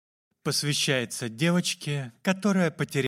Аудиокнига Темная сторона мелодии | Библиотека аудиокниг